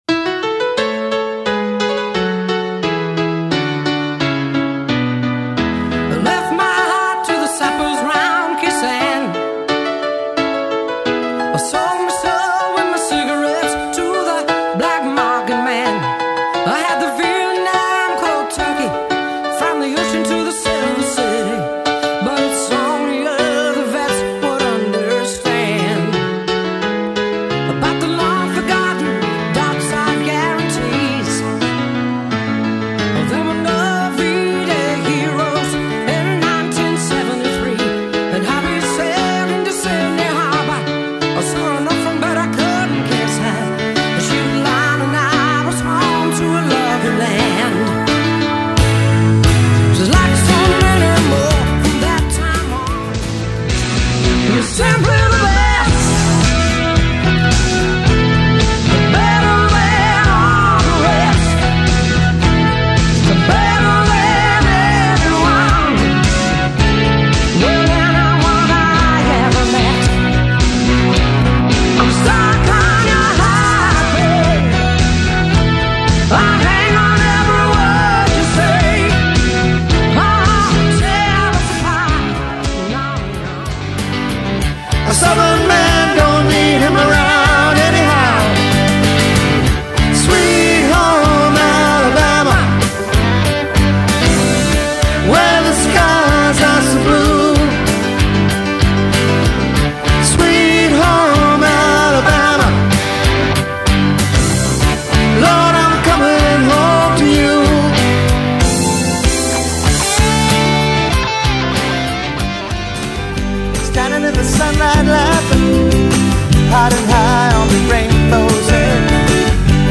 lead guitar